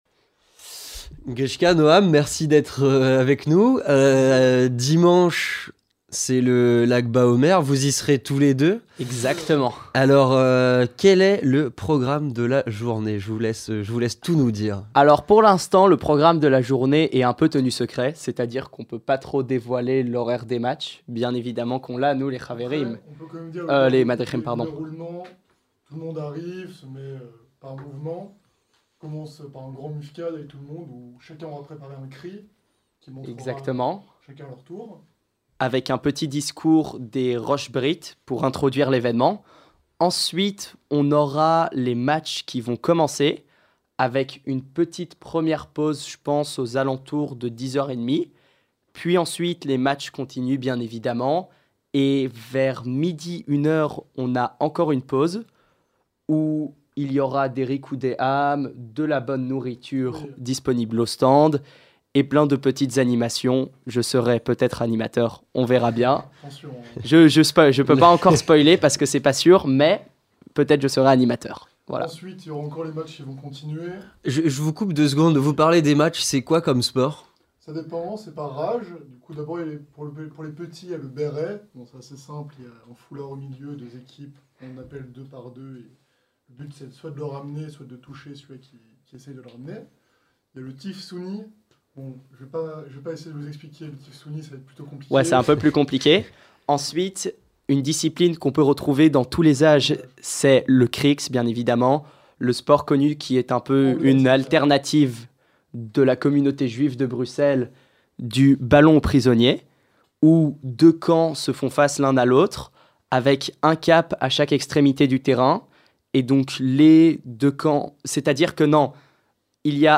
Interview communautaire - Le lag Baomer